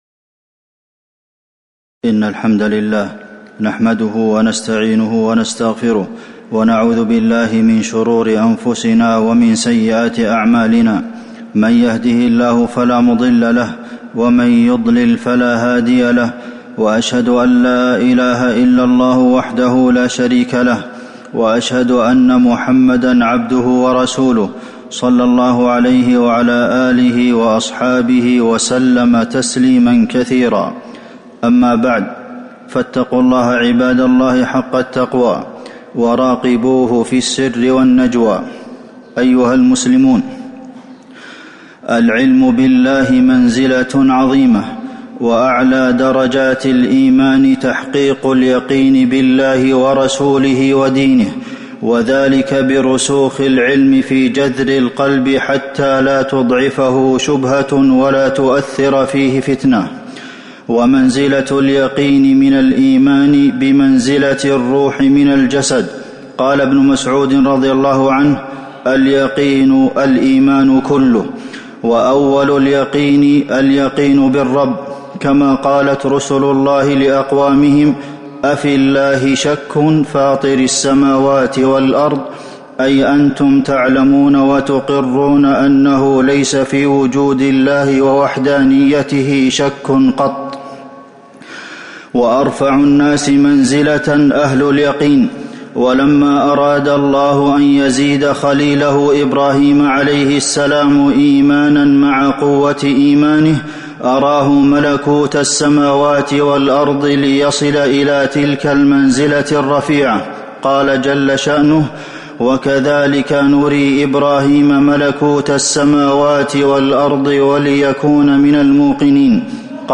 تاريخ النشر ١١ ذو القعدة ١٤٤٣ هـ المكان: المسجد النبوي الشيخ: فضيلة الشيخ د. عبدالمحسن بن محمد القاسم فضيلة الشيخ د. عبدالمحسن بن محمد القاسم اليقين بوجود الله